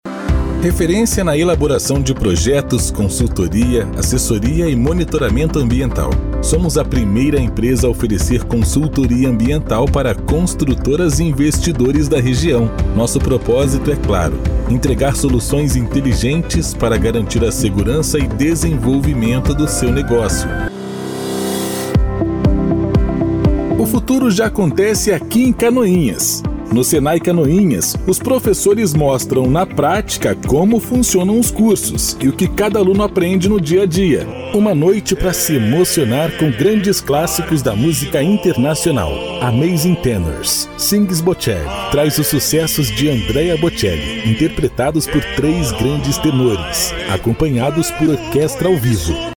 Padrão: